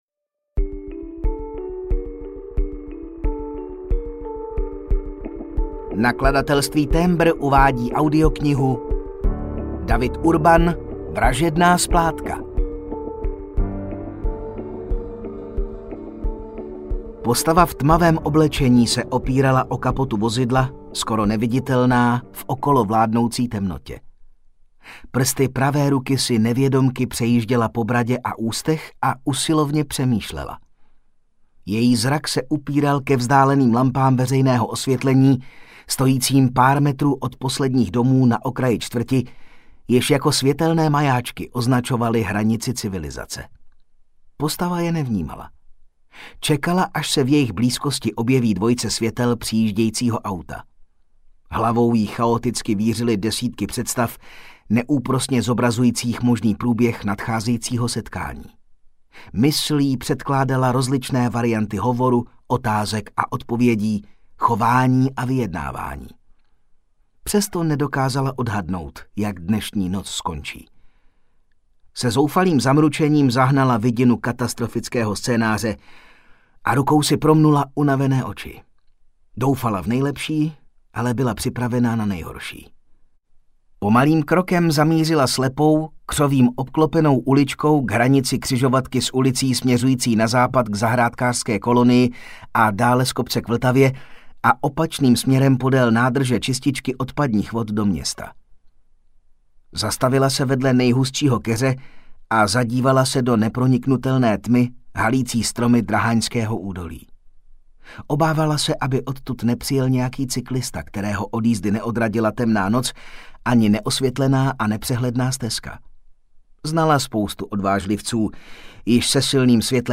Vražedná splátka audiokniha
Ukázka z knihy
Natočeno ve studiu KARPOFON (AudioStory)